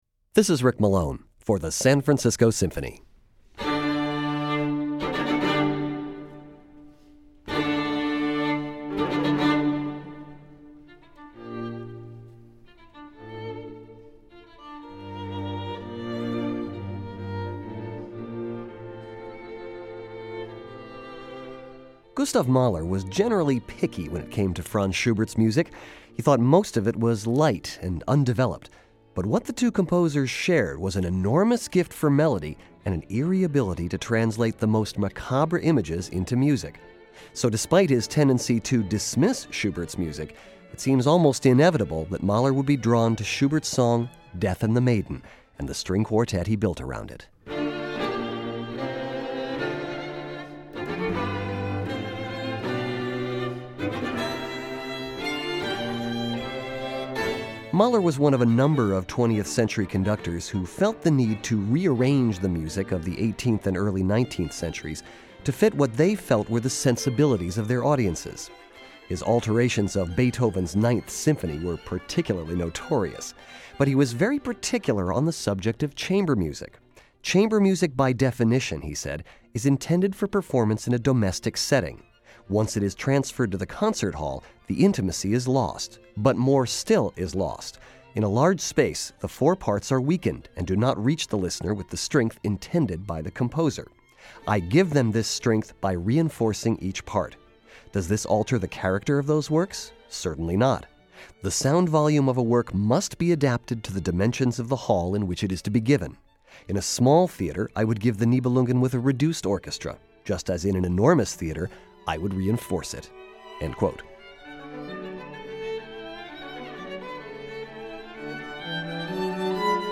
However, in the case of Schubert’s Quartet in D Minor, Death and the Maiden , a theme and variations on his lieder of the same name, the work definitely remains more Schubert’s. Mahler reinforced the bass line, changed double stops into rich string textures, and brought this intimate chamber work into the large concert hall. This podcast uses a recording of the original quartet, performed by SFS musicians
violins
viola
cello.